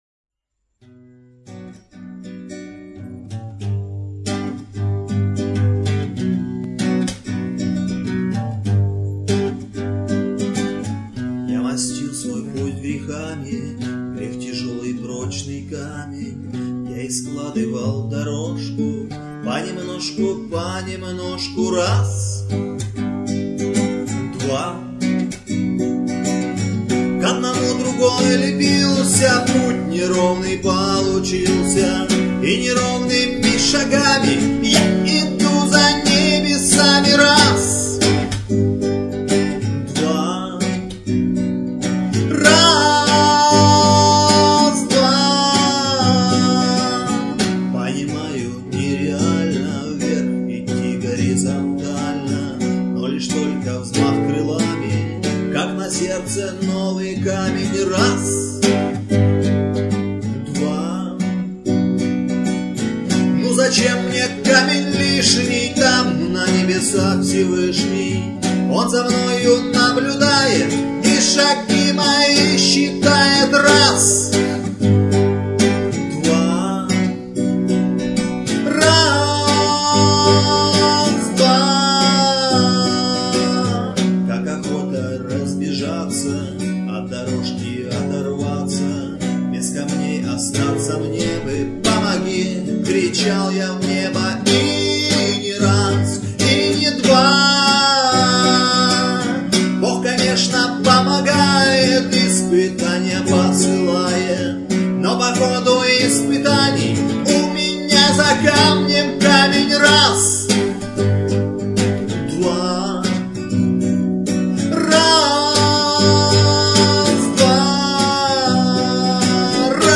Армейские и дворовые песни под гитару